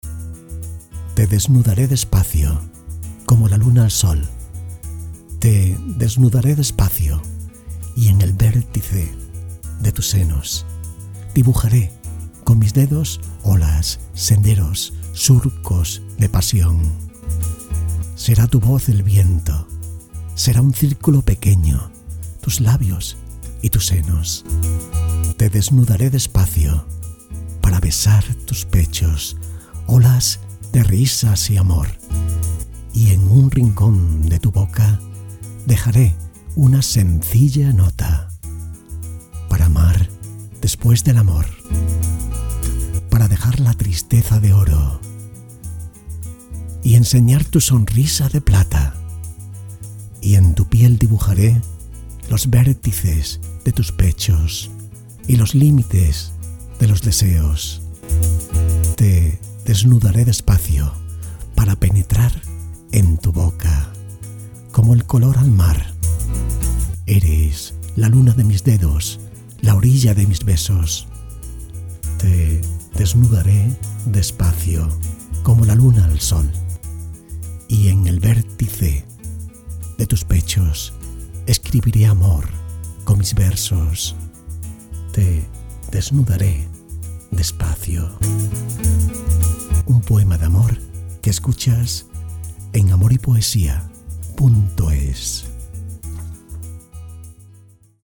Poemas con voz. Poesías con voz. Poemas románticos con voz. Poesías románticas con voz